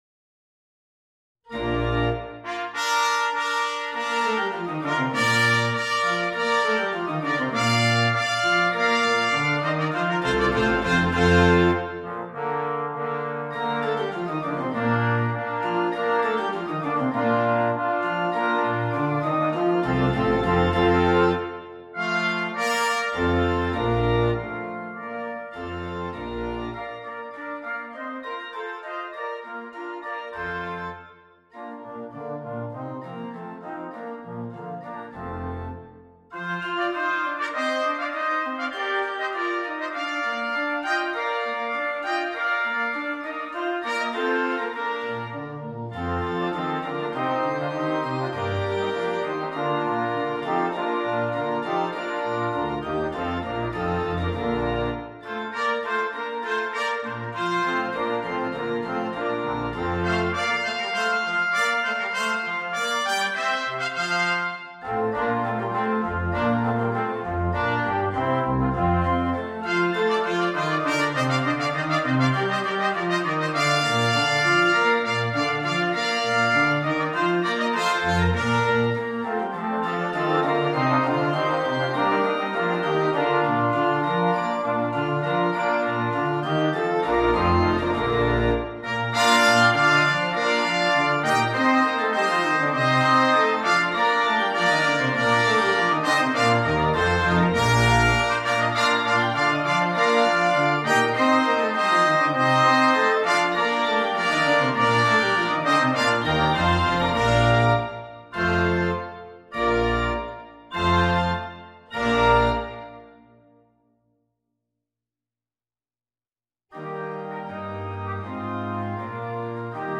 genre Classique